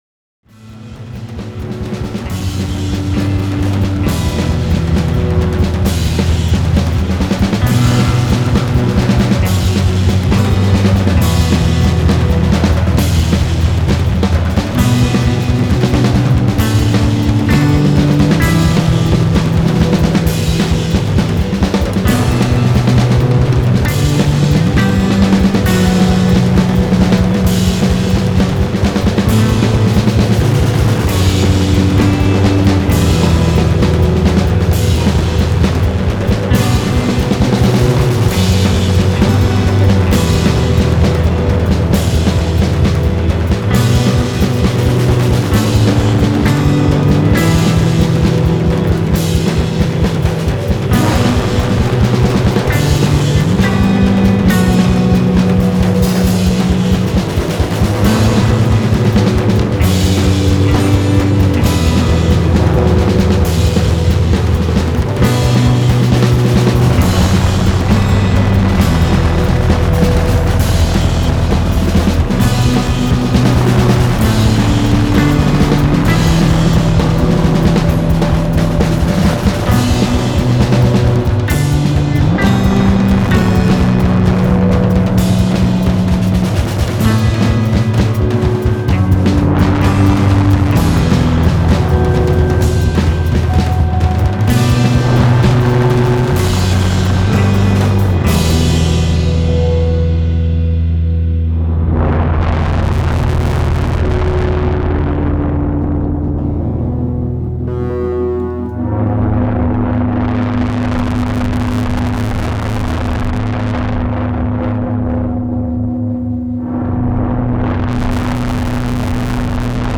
It sounds like Wolfmother tuning up!